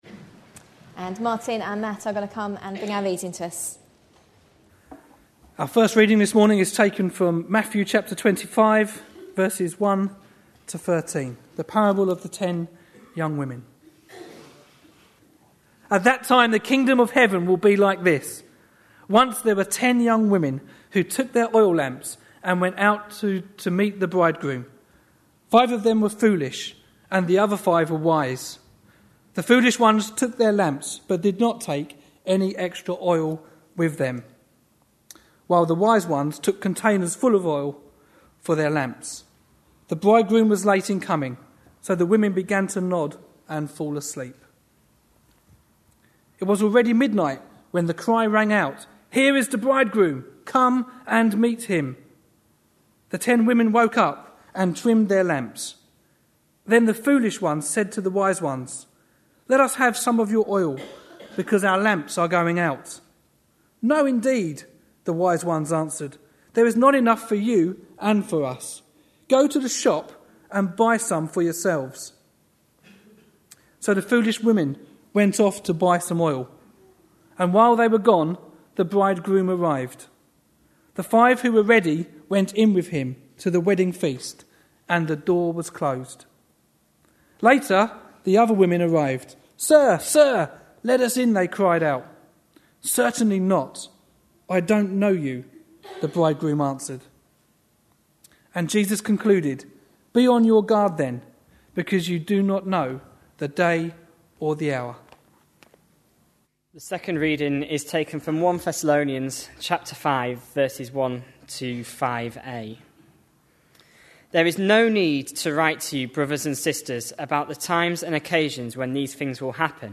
A sermon preached on 28th November, 2010, as part of our Parables of Matthew series.